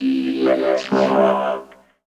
68.5 VOCOD.wav